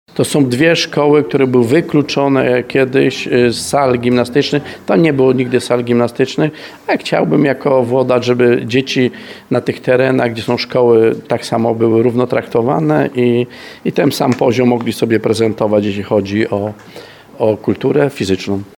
– To kolejna inwestycja tego typu dedykowana uczniom – mówi Stanisław Kuzak, wójt gminy Chełmiec.